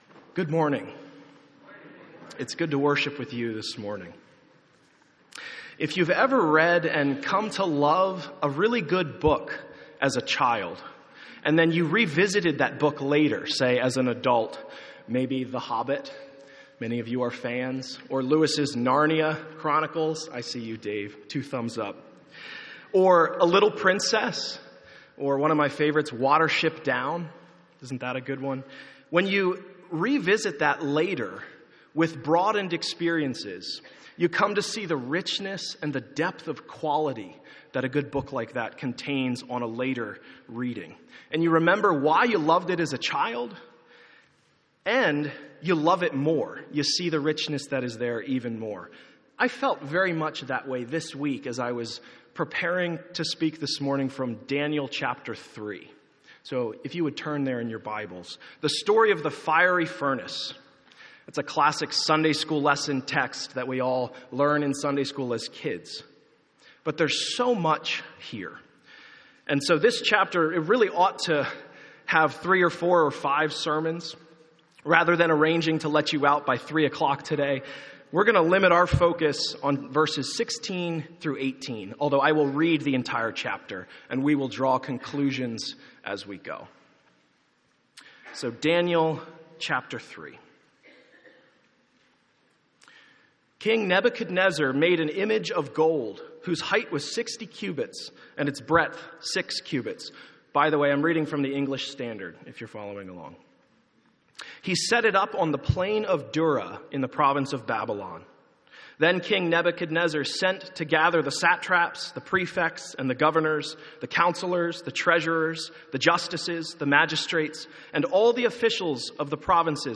Daniel — Audio Sermons — Brick Lane Community Church